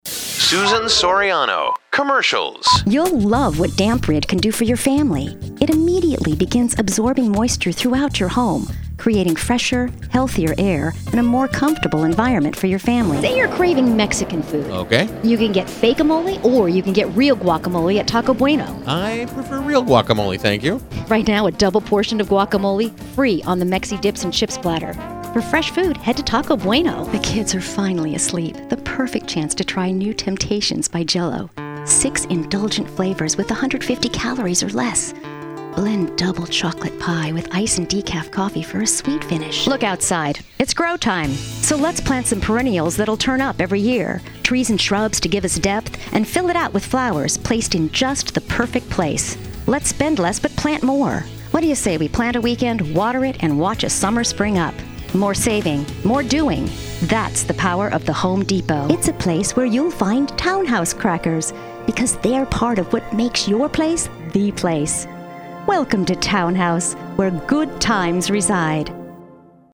Voice Over
Download Commercial Demo